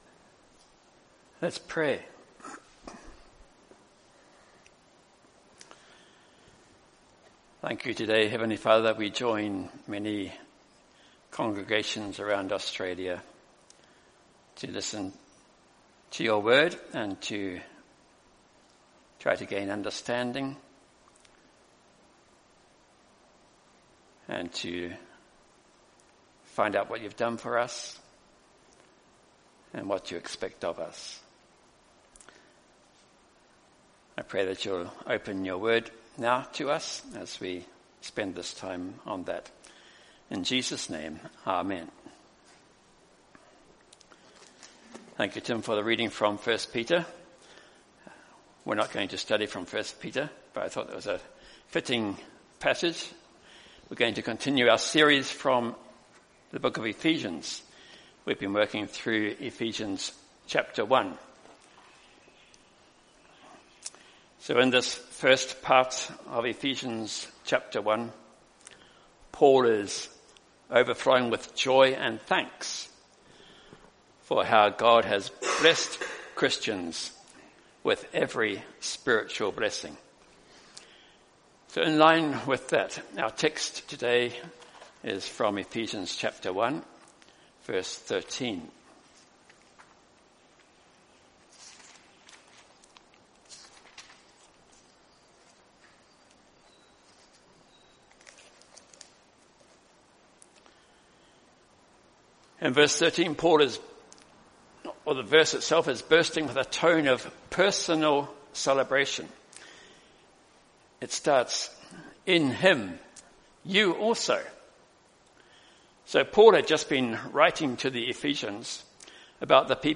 Download mp3 Previous Sermon of This Series Next Sermon of This Series